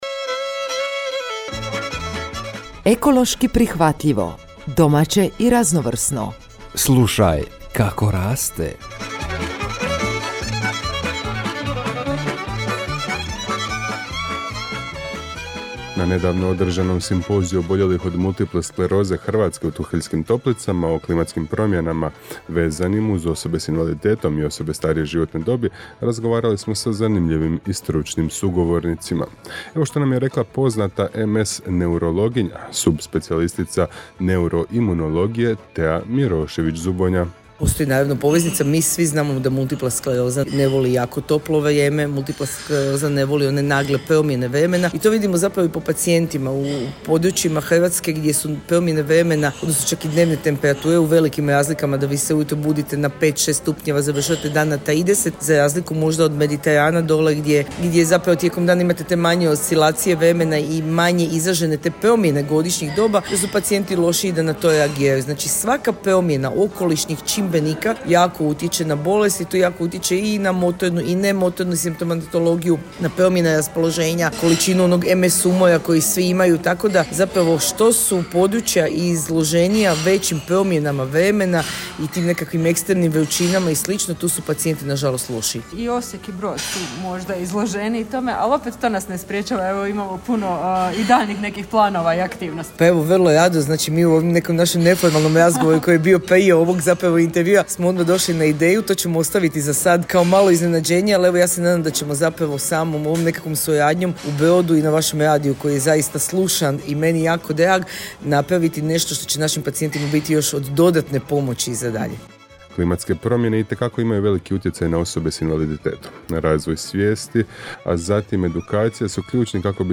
Ovaj edukativni sadržaj objavljujemo u obliku radijske emisije koju možete uvijek ponovno poslušati.